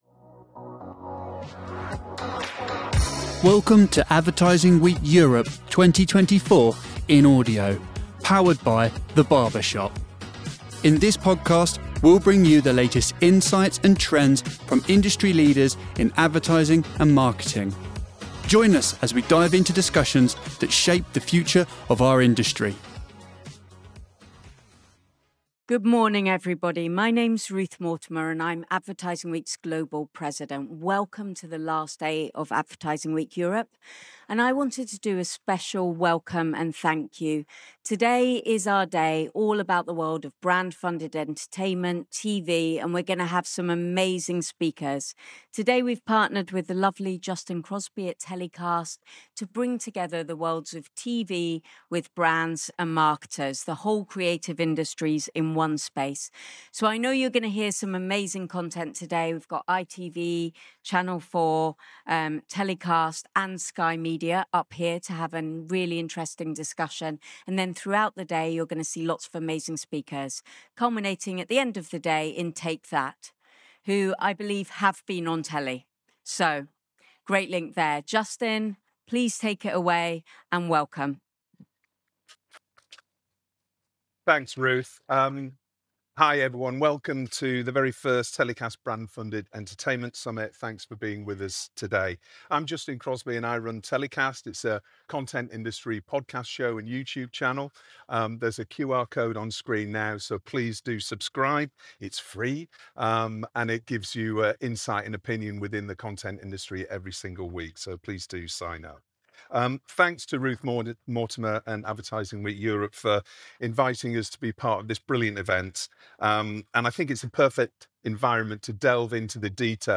Learn about the criteria broadcasters use to evaluate content, the role of branded entertainment, and the future trends shaping TV commissioning. This discussion provides invaluable guidance for content creators and marketers looking to pitch their ideas and secure broadcaster partnerships.